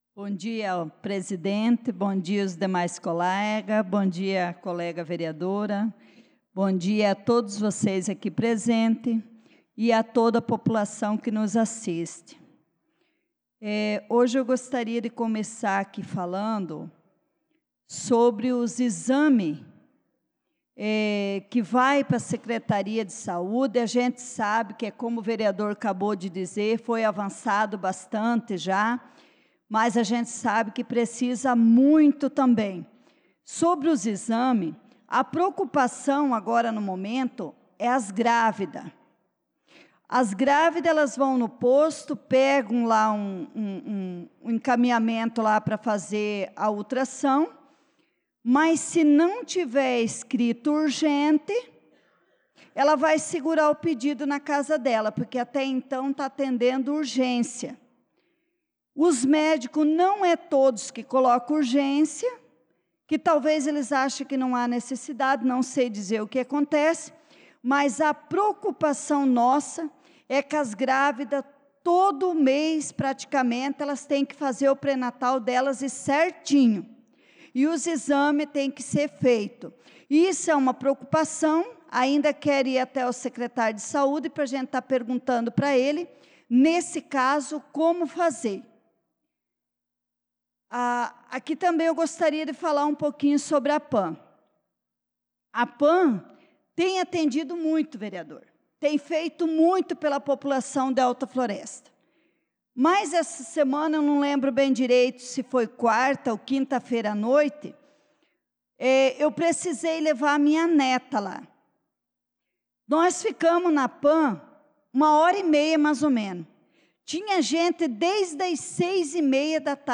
Pronunciamento da Vereadora Leonice Klaus na Sessão Ordinária do dia 25/03/2025